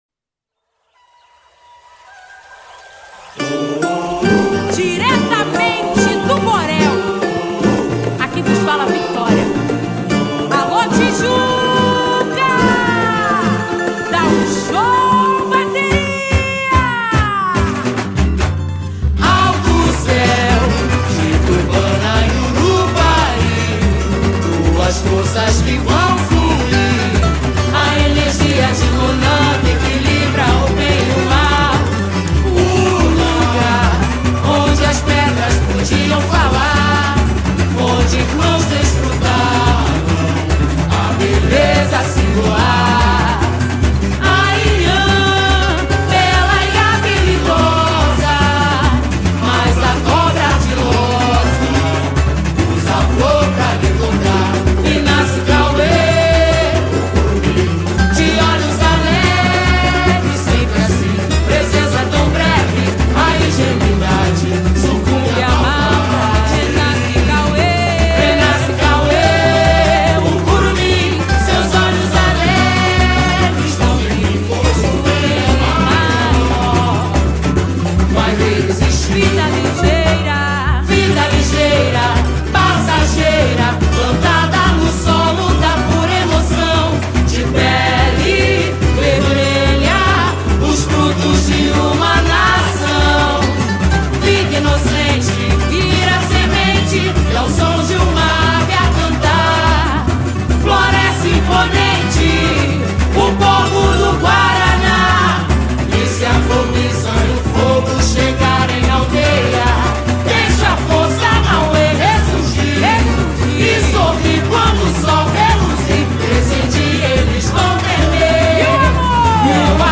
samba-enredo